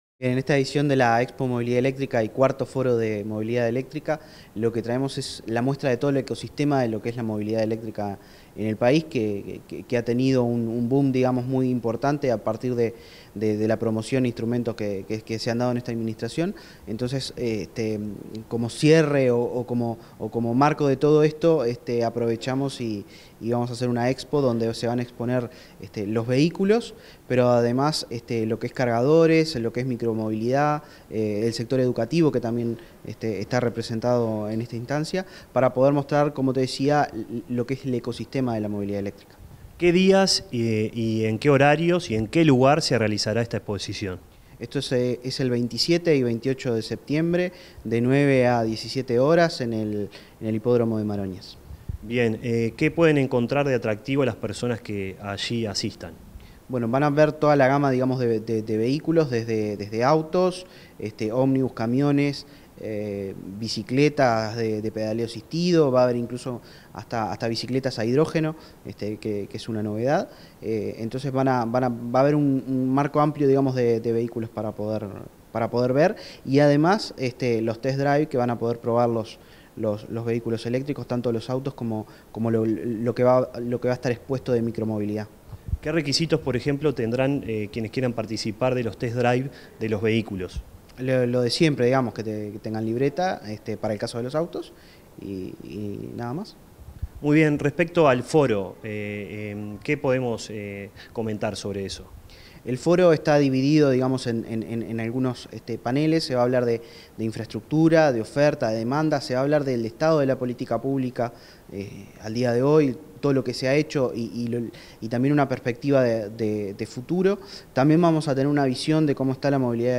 Entrevista al director nacional de Energía, Christian Nieves